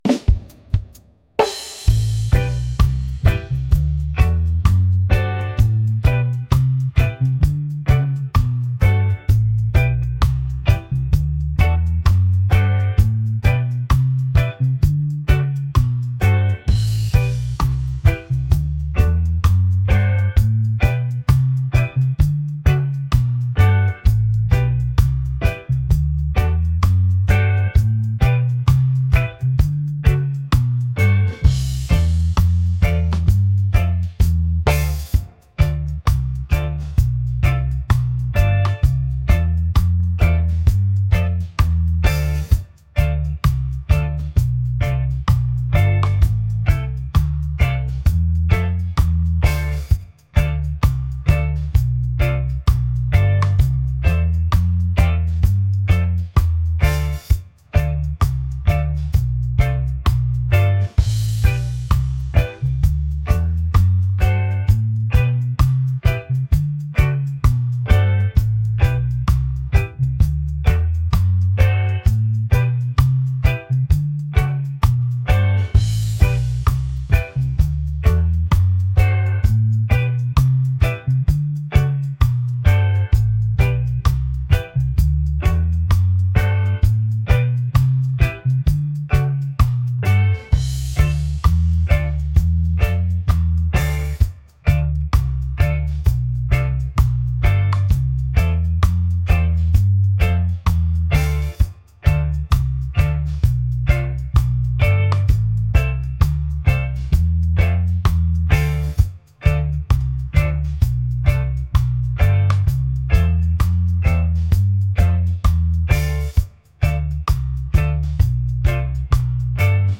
groovy | soulful | reggae